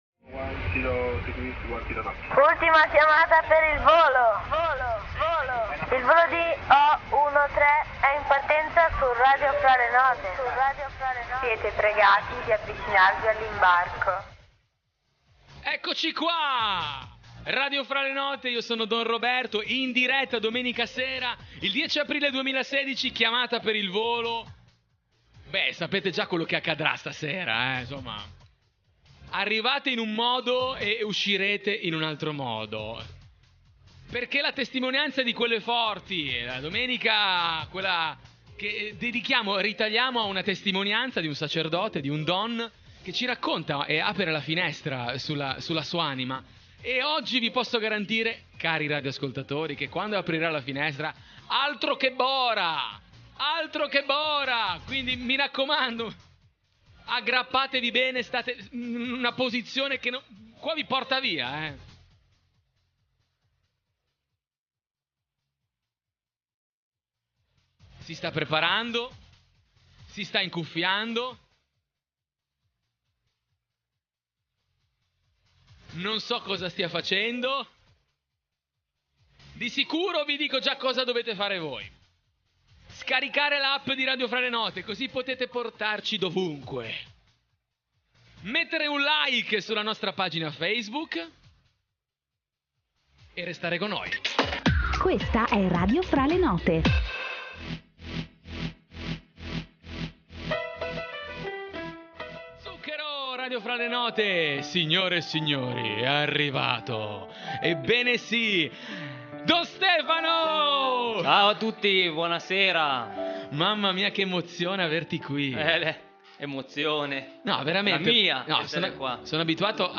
In onda la domenica sera alle 21:00 in diretta dalla Sede centrale di Radio Fra le note in Via Minoretti di Genova.